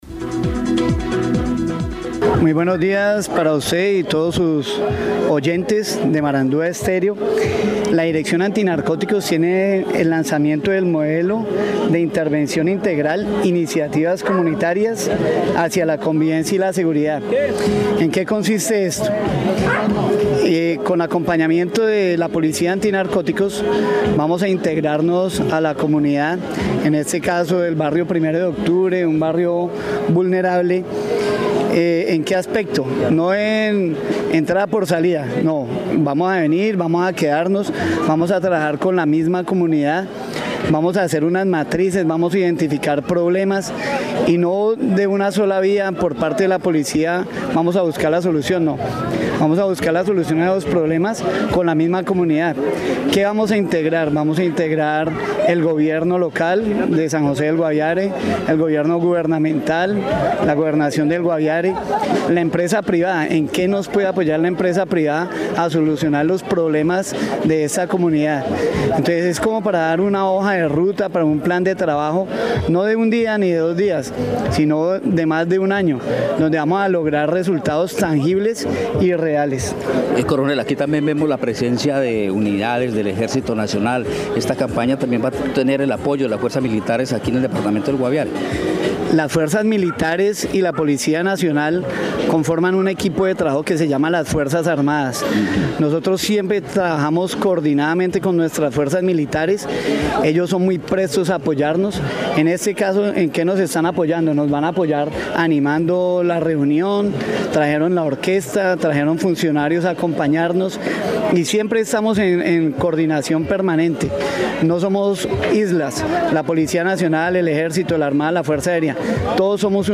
Escuche al Coronel Pablo Cárdenas, comandante de la Compañía Antinarcóticos en Guaviare.